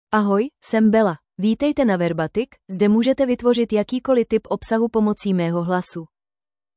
FemaleCzech (Czech Republic)
BellaFemale Czech AI voice
Bella is a female AI voice for Czech (Czech Republic).
Voice sample
Listen to Bella's female Czech voice.